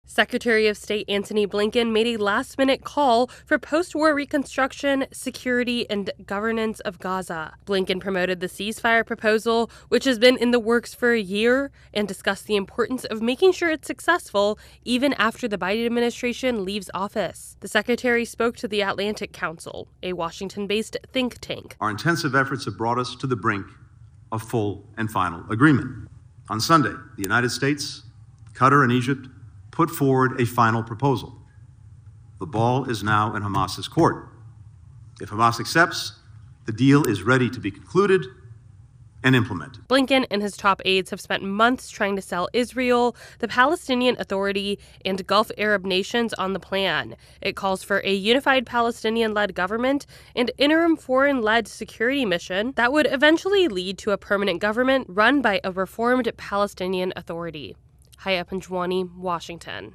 reports on Secretary of State Blinken's remarks on Mideast tensions.